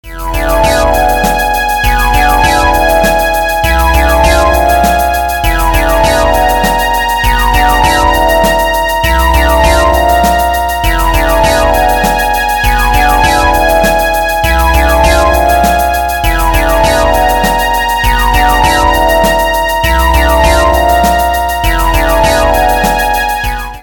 "Kibo o Ushinatta Toki" è un brano/listato per Sonic Pi che scrissi nell'estate del 2019.